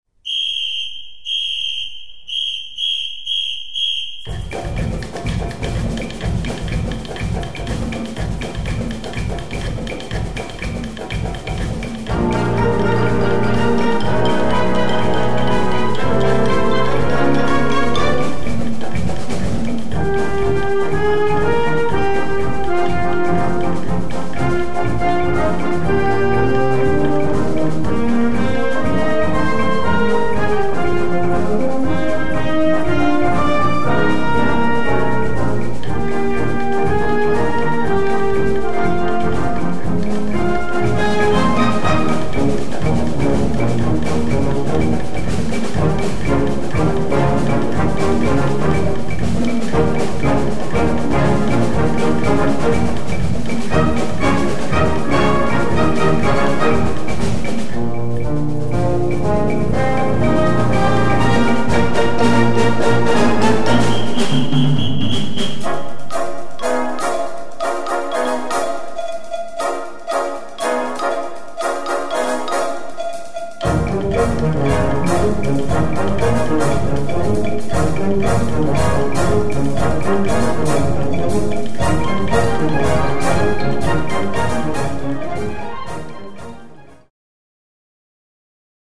Konzertwerk
Blasorchester